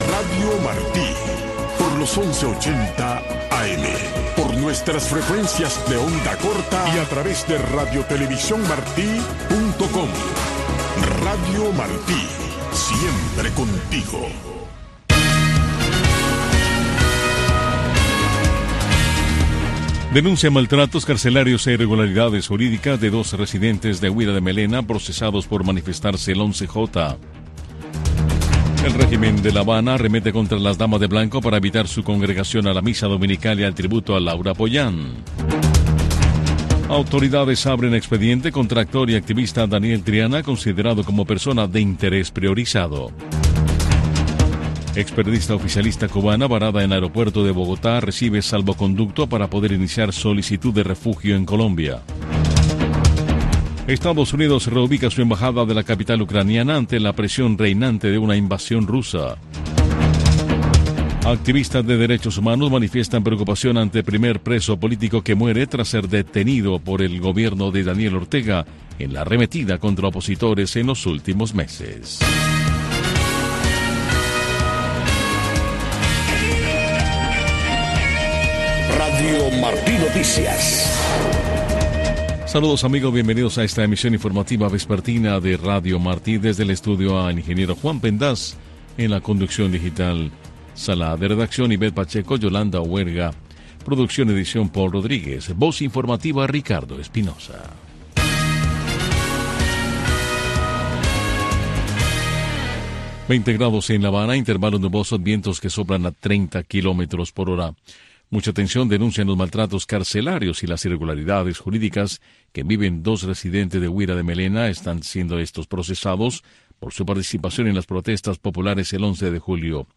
Noticiero de Radio Martí 6:00 PM